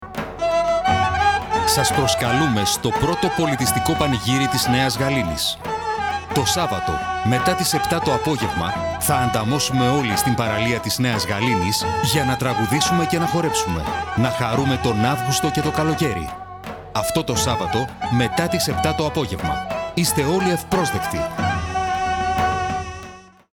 Πανηγύρι-Spot.mp3